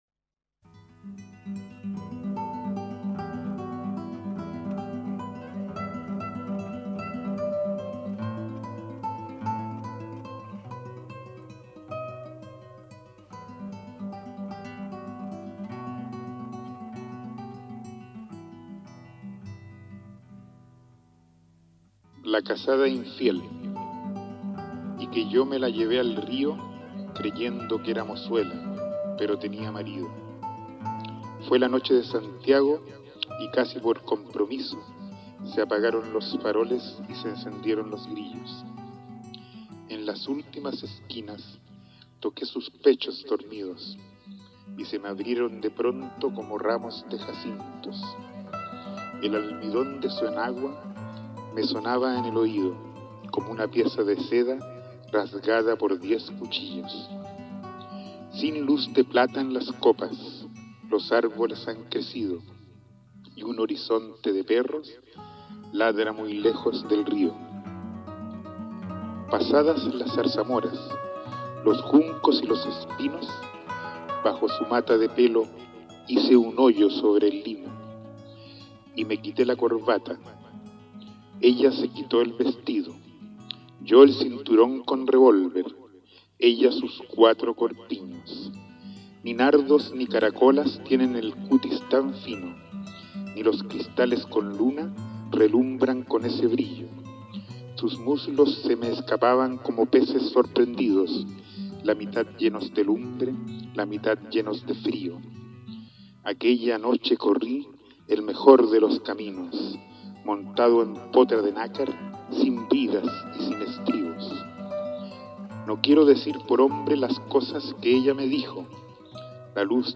La Casada infiel - Federico García Lorca (recitado)